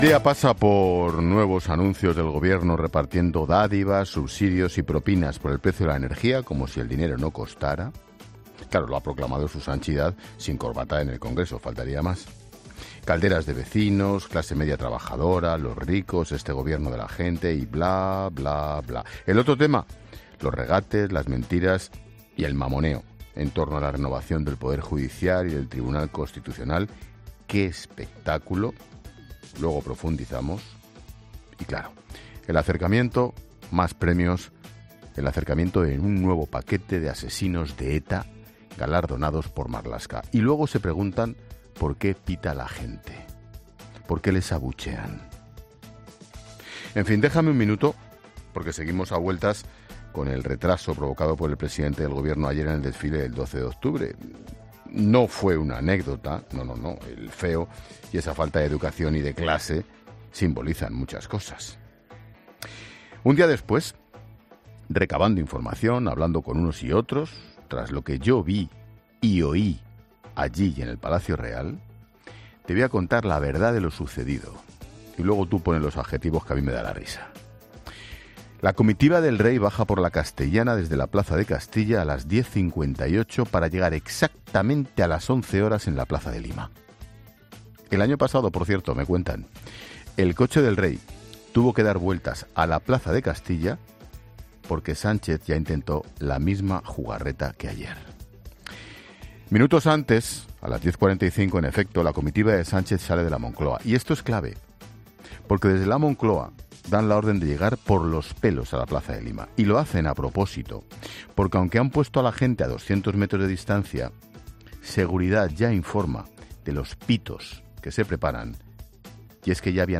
Monólogo de Expósito
El director de 'La Linterna', Ángel Expósito, habla en su monólogo sobre todo lo que ha dado de sí el 12 de octubre y más asuntos de actualidad